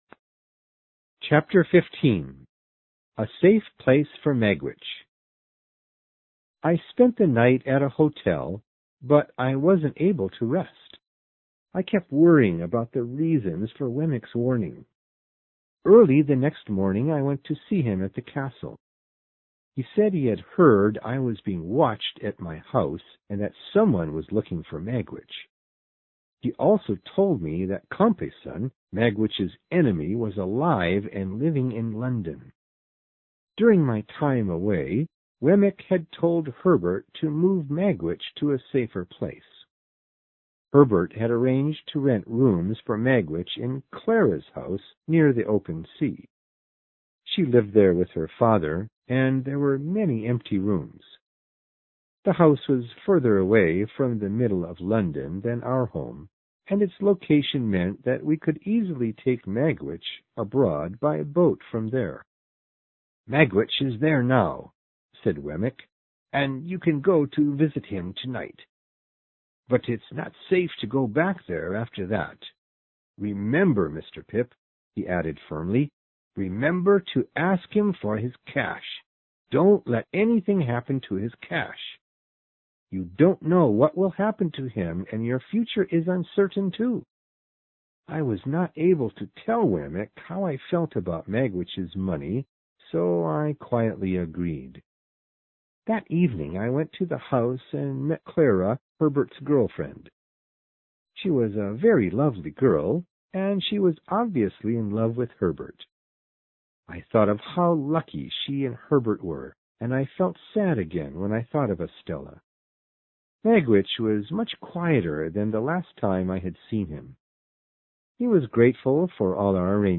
有声名著之远大前程15 听力文件下载—在线英语听力室